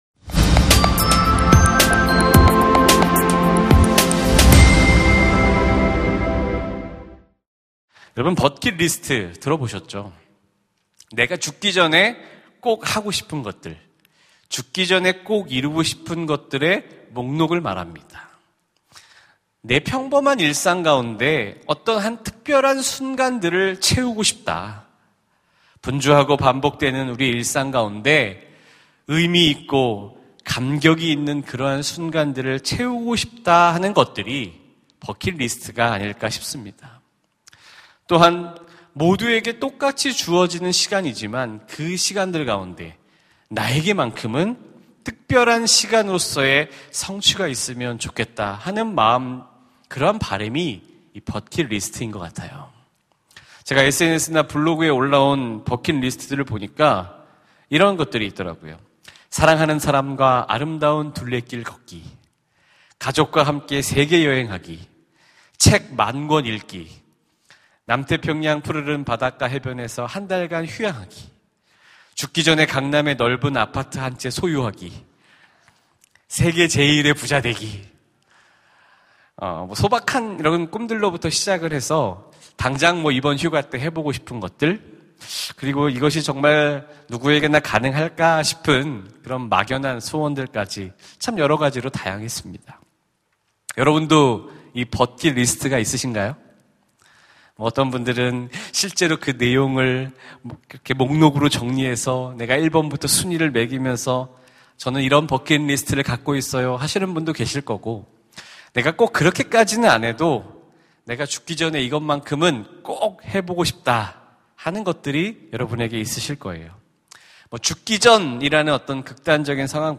설교 : 금요심야기도회